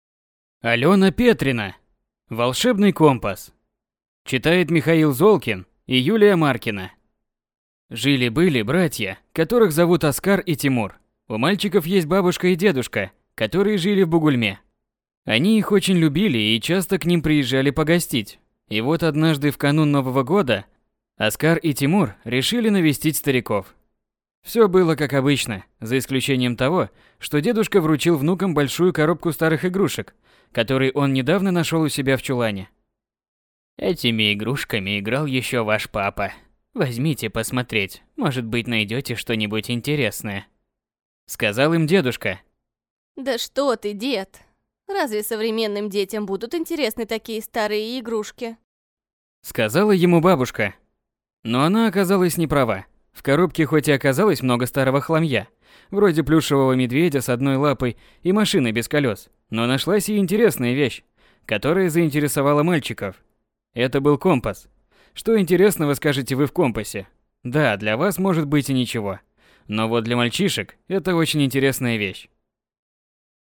Аудиокнига Волшебный компас | Библиотека аудиокниг